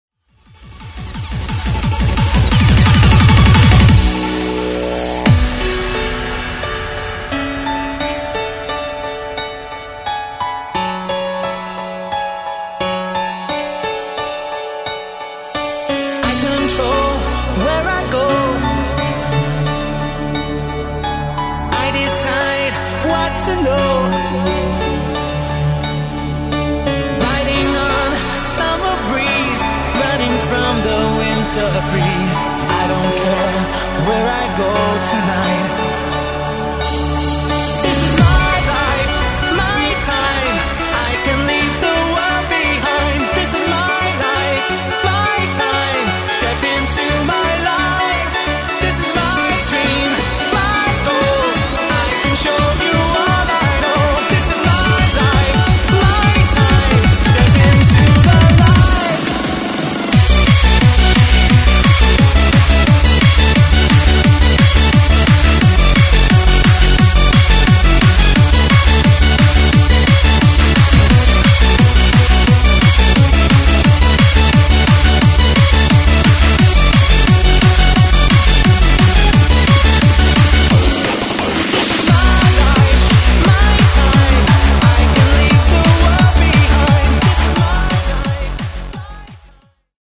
Freeform/Hardcore/Happy Hardcore